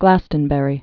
(glăstən-bĕrē)